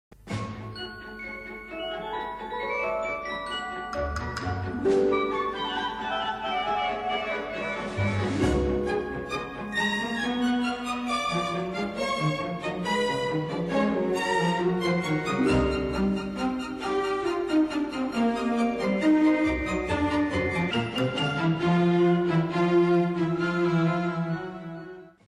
Suite in 4 Sätzen für Kleines Orchester